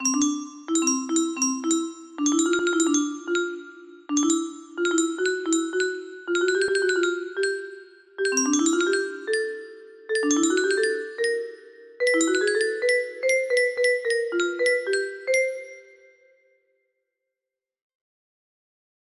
Unknown Artist - Untitl music box melody